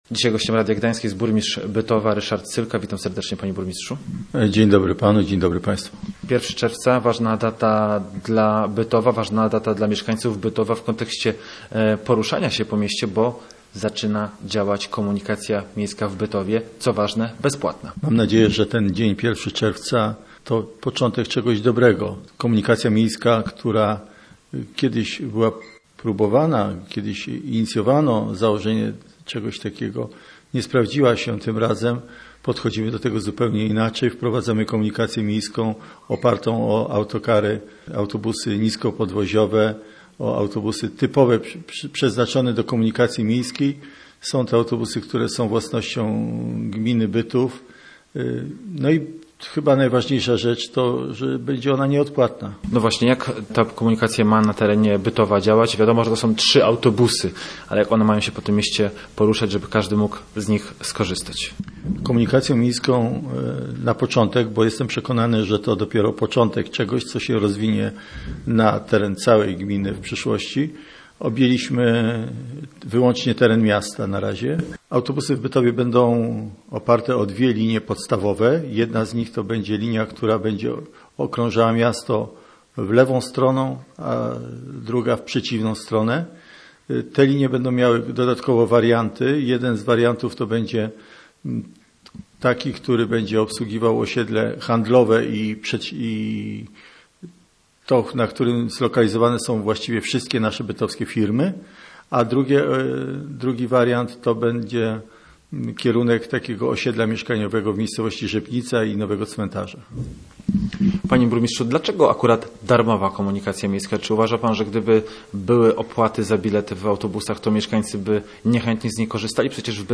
Uruchomiono dwie linie, które będą kursowały po całym mieście – mówił o tym we wtorek Ryszard Sylka, burmistrz Bytowa, który był gościem programu miejskiego Radia Gdańsk w Słupsku.